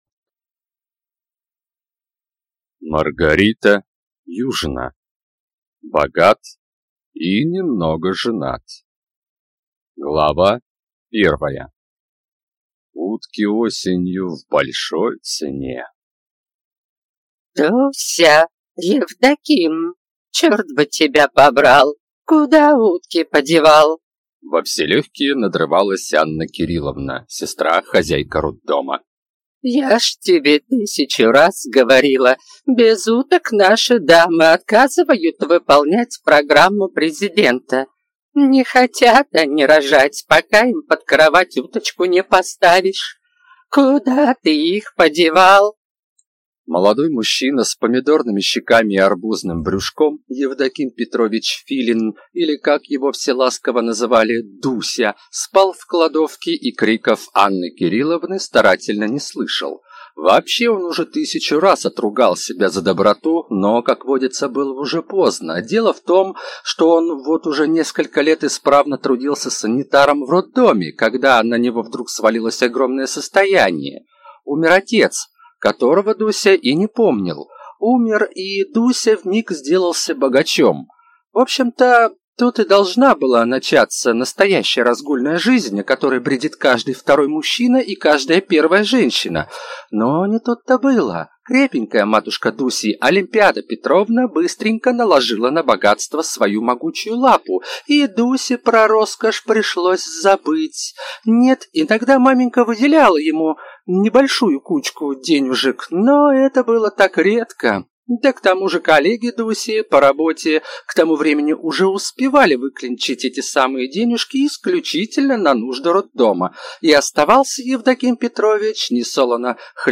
Аудиокнига Богат и немного женат | Библиотека аудиокниг